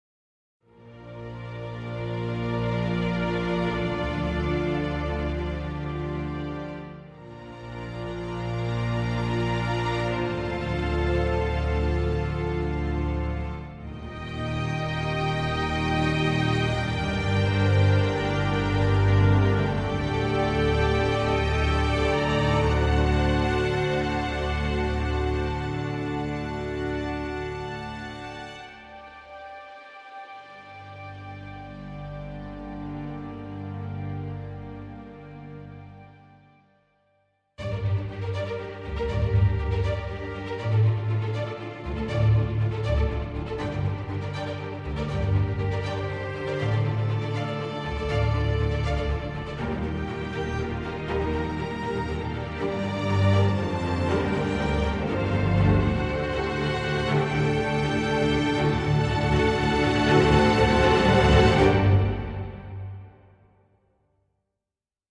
细致入微的弦乐器库
精细和清晰的声音
四个麦克风混音，带有混音台界面